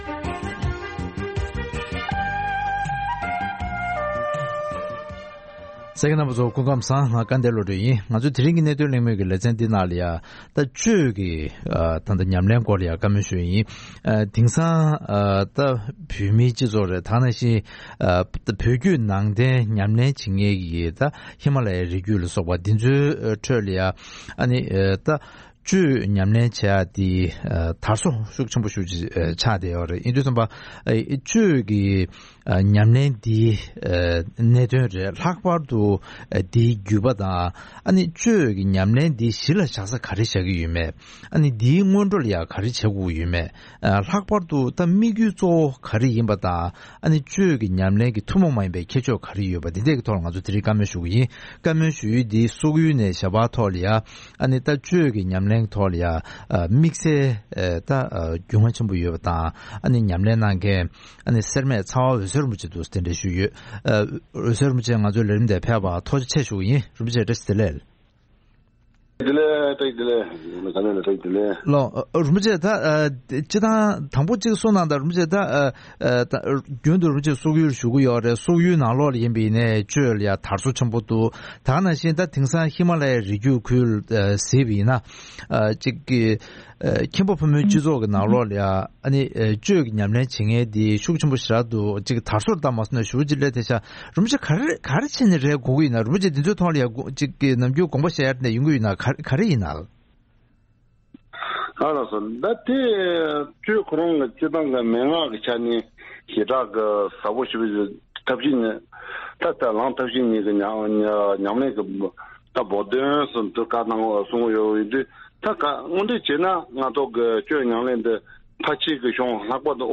དེང་སྐབས་བོད་མིའི་སྤྱི་ཚོགས་ནང་གཅོད་ཀྱི་ཉམས་ལེན་དར་སྤེལ་ཤུགས་ཆེ་འབྱུང་བཞིན་པར་བརྟེན་གཅོད་ཀྱི་འབྱུང་རིམ་དང་ཉམས་ལེན་བྱ་ཕྱོགས་ཐད་གླེང་མོལ་གནང་བ།